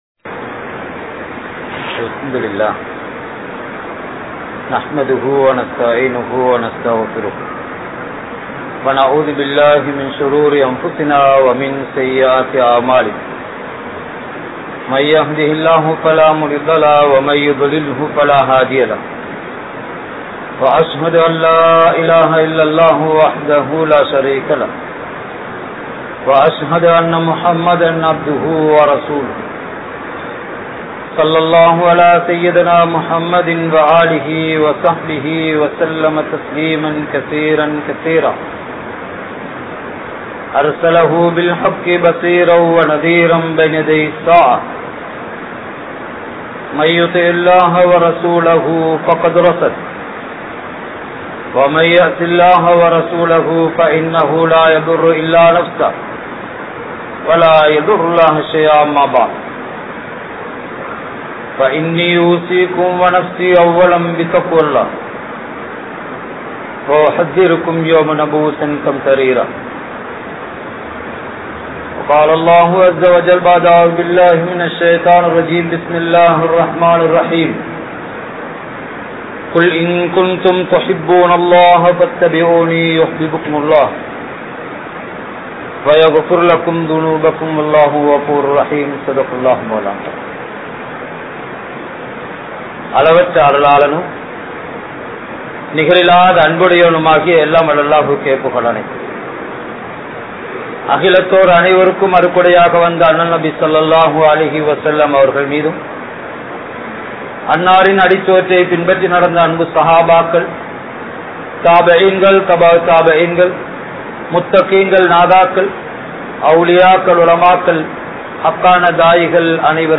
Unmaiyaana Anpu Ethu? (உண்மையான அன்பு எது?) | Audio Bayans | All Ceylon Muslim Youth Community | Addalaichenai